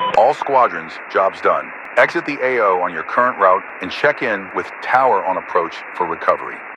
Radio-commandMissionComplete3.ogg